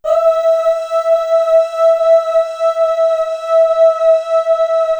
Index of /90_sSampleCDs/Best Service ProSamples vol.55 - Retro Sampler [AKAI] 1CD/Partition C/CHOIR UHH